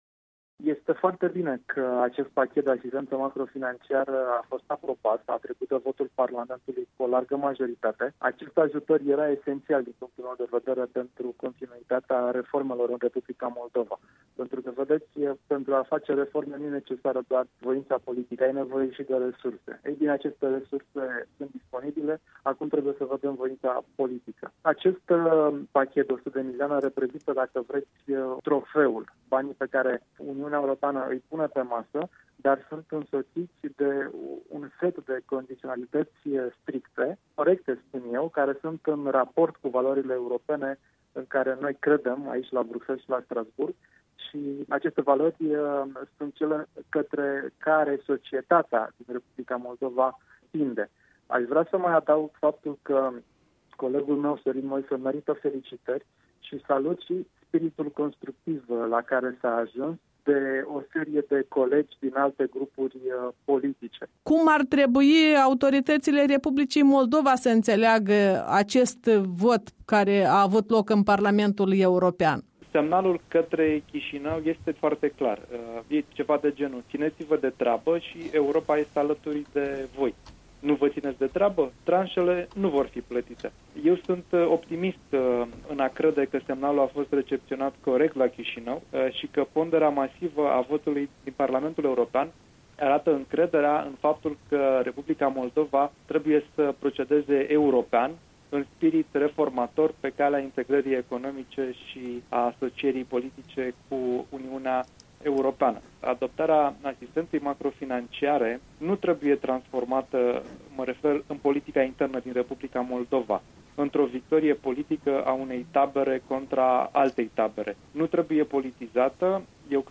Interviu cu europarlamentarul Andi Cristea